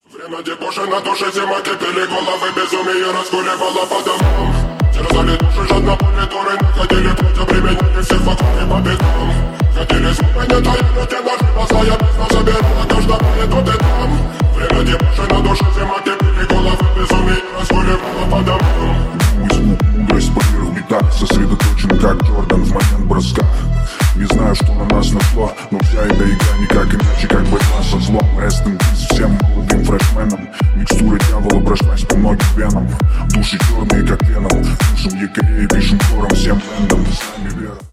ремиксы
рэп , басы